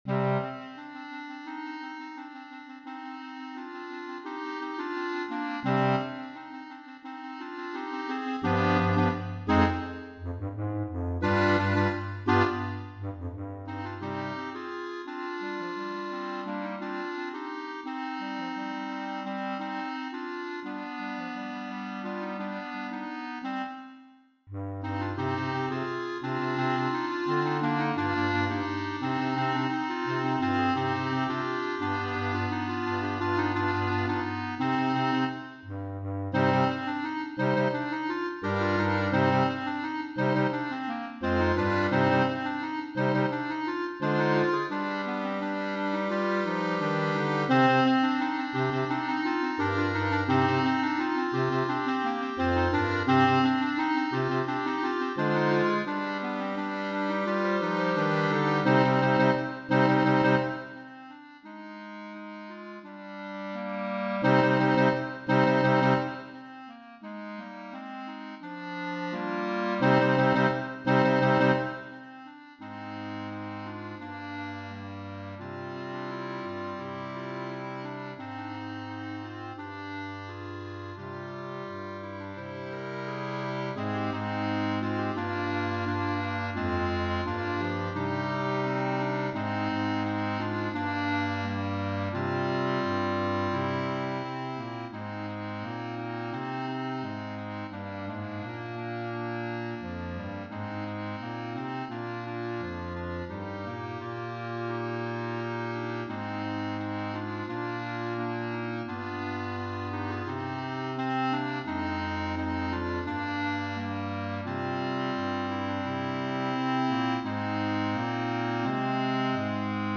Voicing: Clarinet Quintet